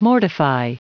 Prononciation du mot mortify en anglais (fichier audio)
Prononciation du mot : mortify